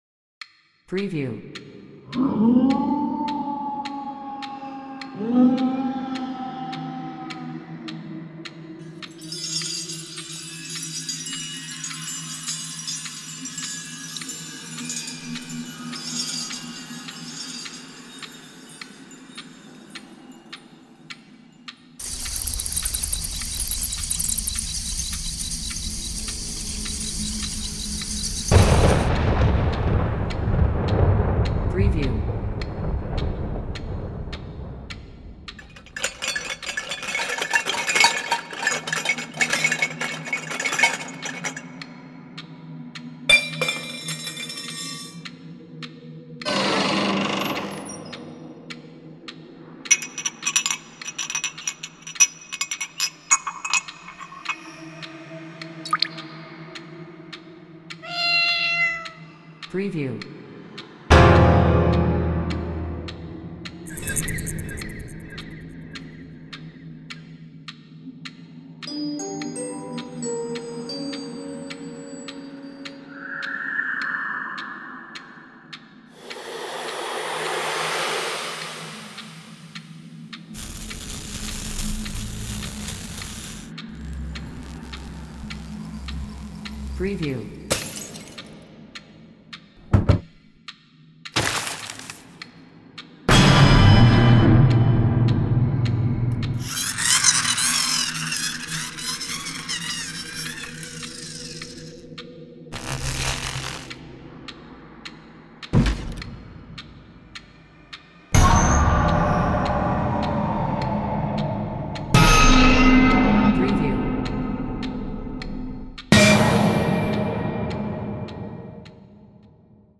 Dreadtone Spooky Sounds | Audio Sound FX | Unity Asset Store
Sound FX
Dreadtone_Spooky_Sounds.mp3